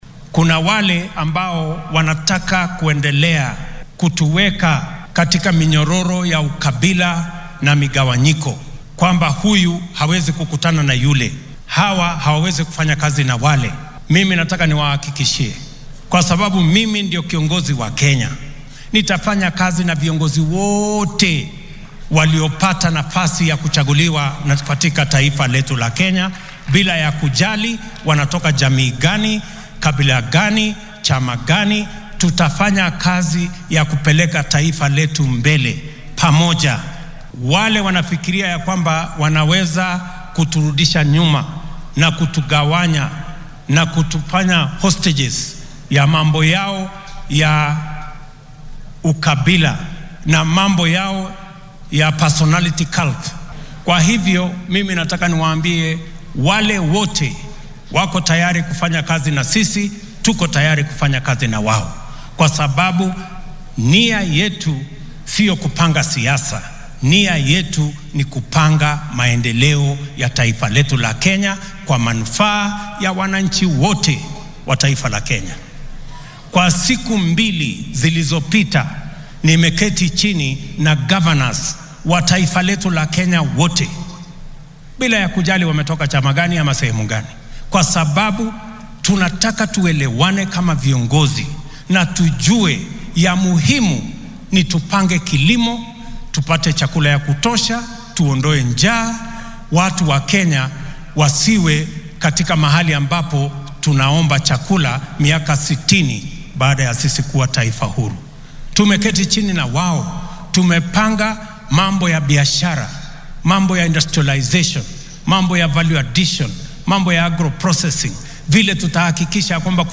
Hadalkan ayuu shalay ka sheegay ismaamulka Nakuru oo uu uga qayb galay munaasabad duco ah.
William-Ruto.mp3